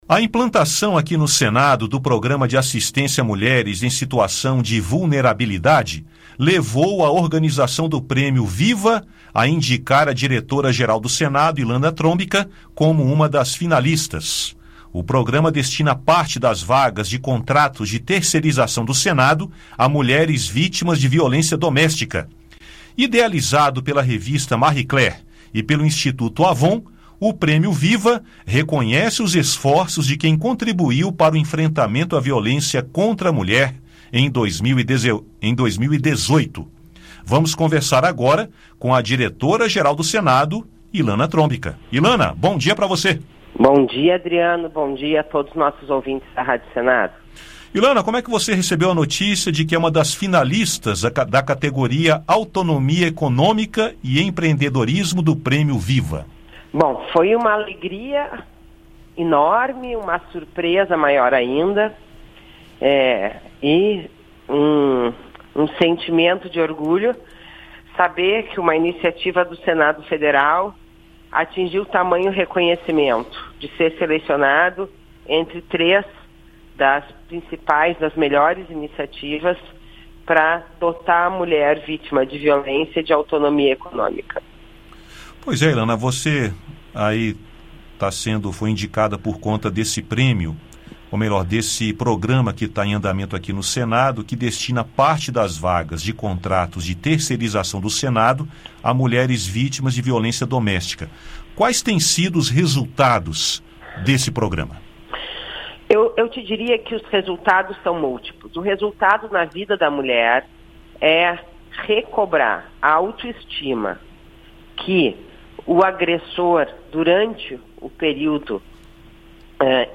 Ouça áudio com uma entrevista com Ilana Trombka.